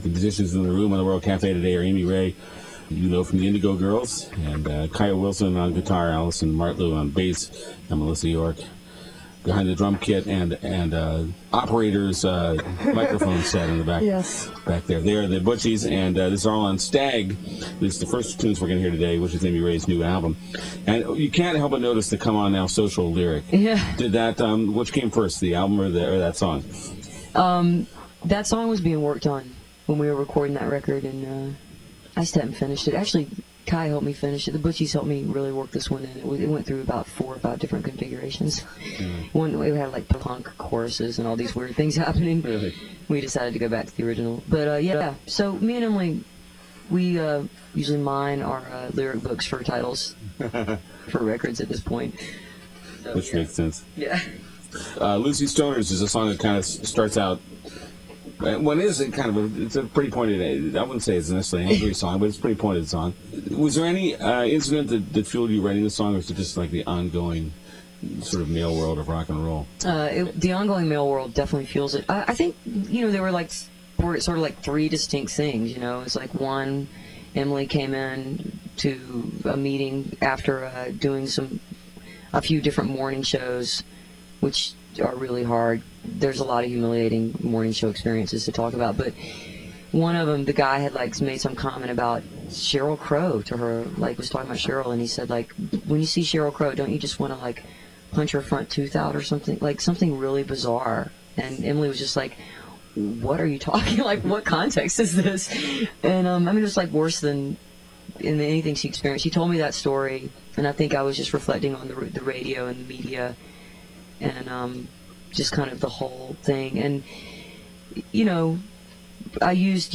03. interview (2:40)